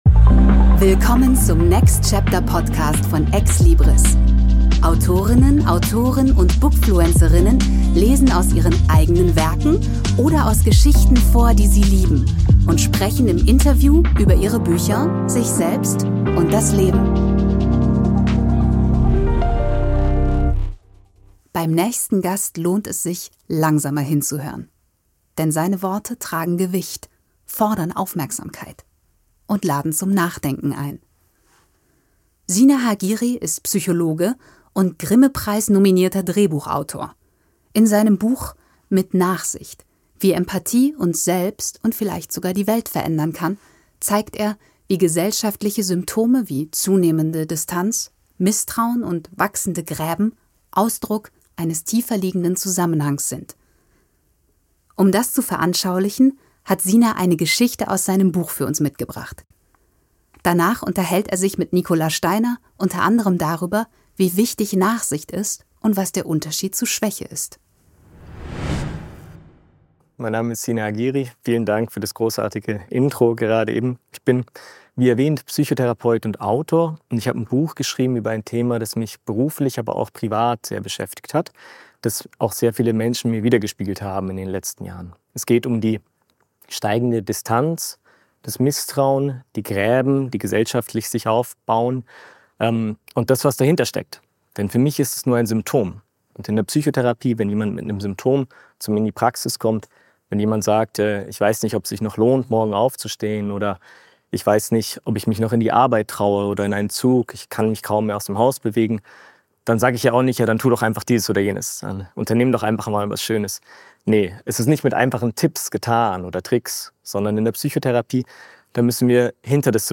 Beschreibung vor 7 Monaten Beim nächsten Gast lohnt es sich, langsamer hinzuhören.